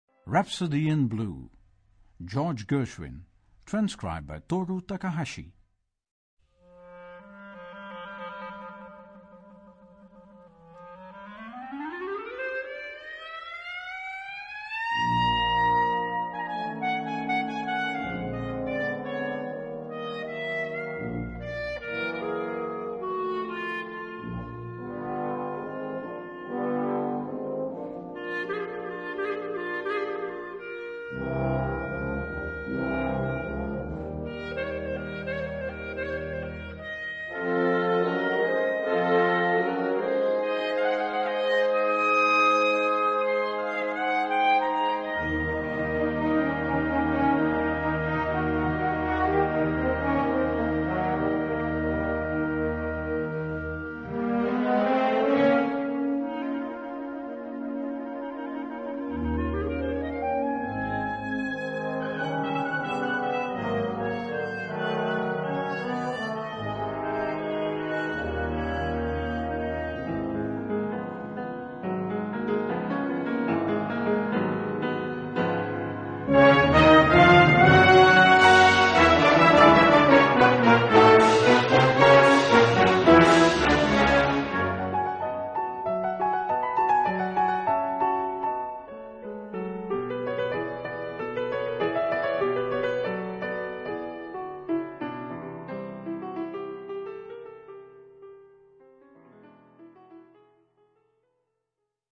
B-flat Major（原調）
ピアノ